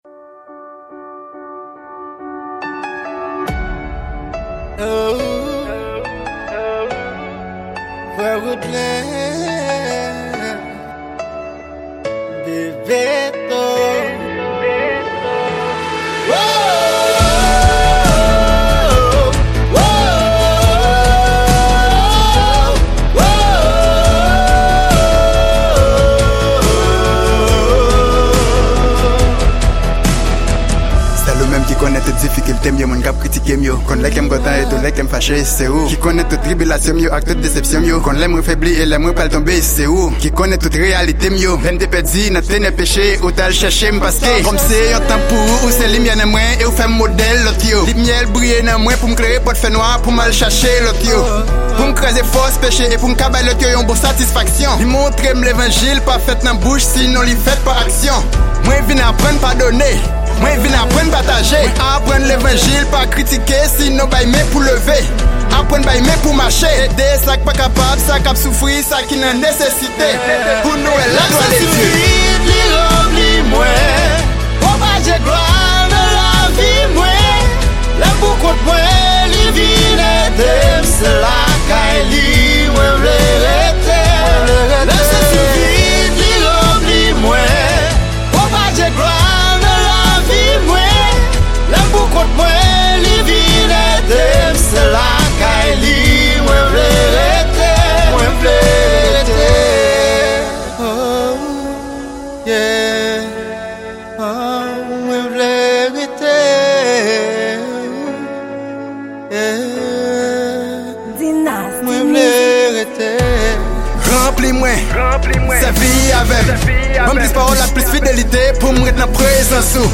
Genre: Rap Gospel